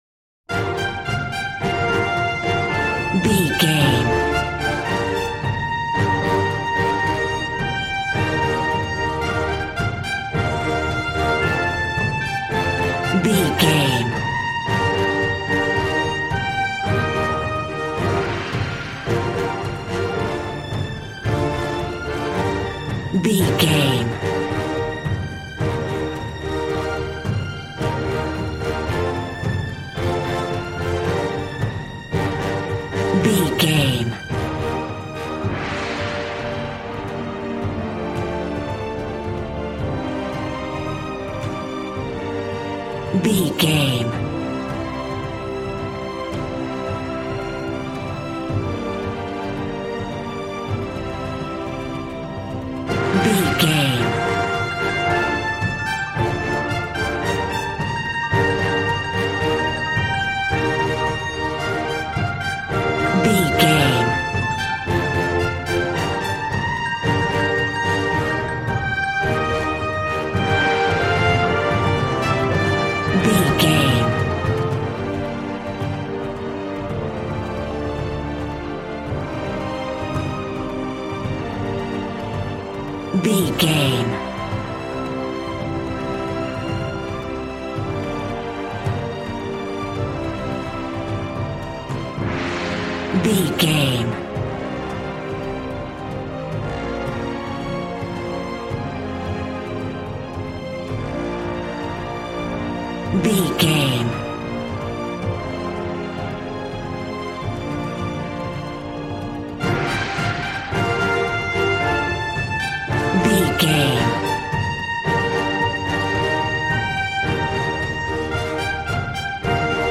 Regal and romantic, a classy piece of classical music.
Aeolian/Minor
regal
cello
double bass